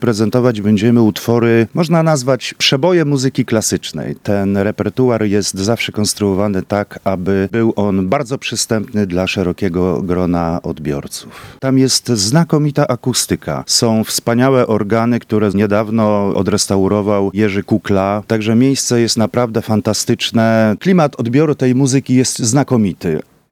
w rozmowie z Radiem Lublin